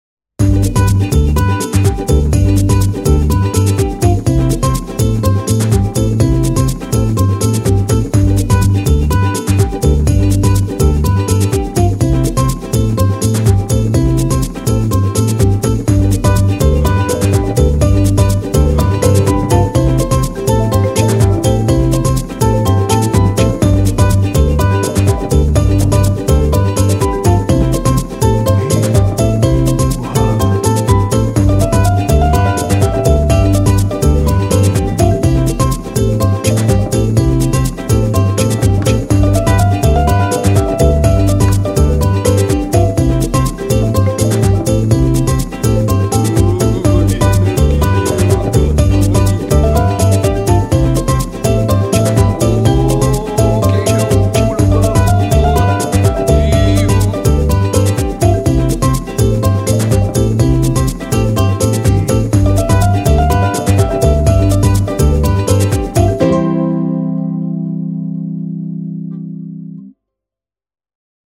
FAST UKULELE